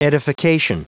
Prononciation audio / Fichier audio de EDIFICATION en anglais
Prononciation du mot edification en anglais (fichier audio)